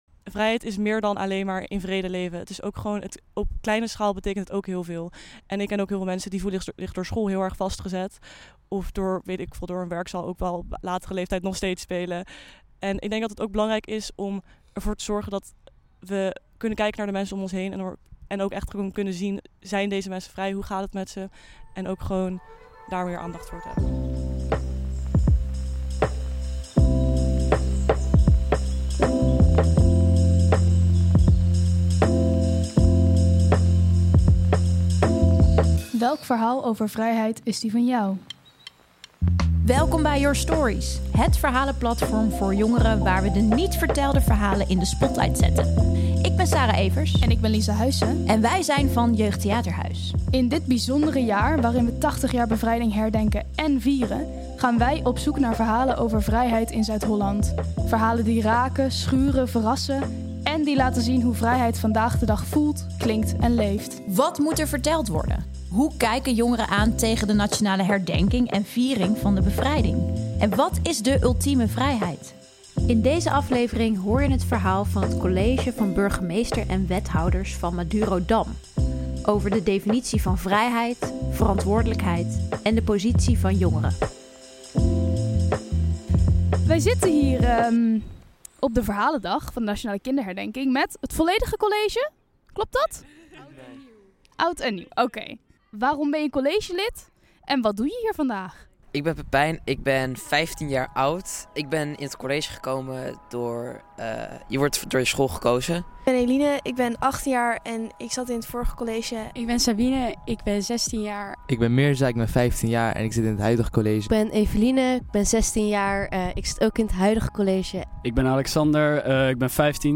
In deze aflevering hoor je de verhalen van het college Burgemeesters en Wethouders van Madurodam over vrijheid. Deze groep betrokken Haagse jongeren (15-18) vertellen over de definitie van vrijheid, verantwoordelijkheid en de positie van jongeren in de maatschappij.